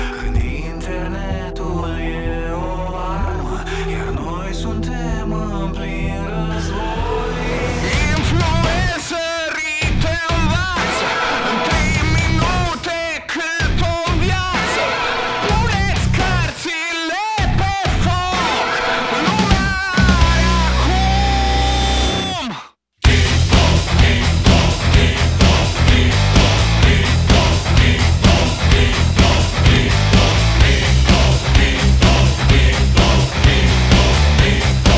Rock Metal
Жанр: Рок / Метал